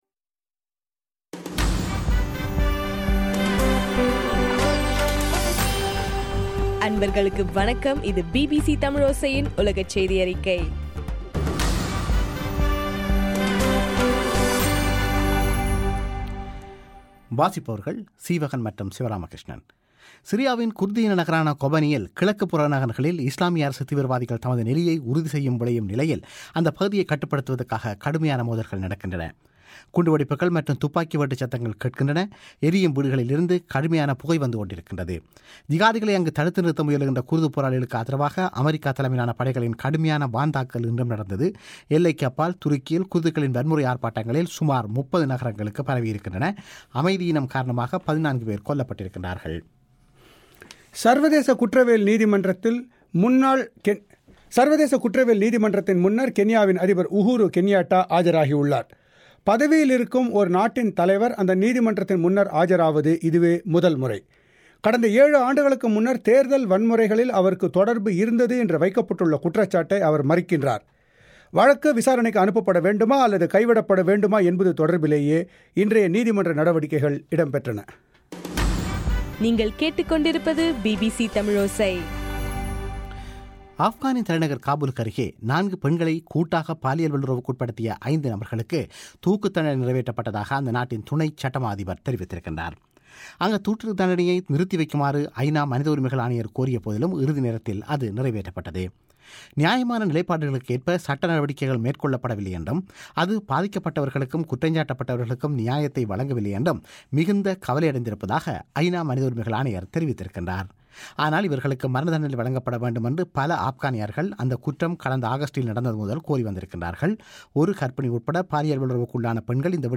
அக்டோபர் 08 - பிபிசி உலகச் செய்திகள்